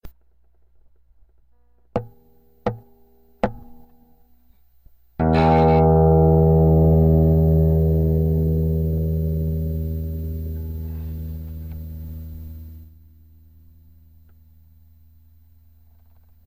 ・それぞれの出力をミキサー経由で左右に振り分けてＰＣに取り込む。
カウントのあと６弦開放を
「ボーーん」と１発
右から、ピエゾの音、左から、マグネチックの音
カウントの音が拾えてない方が、マグネチック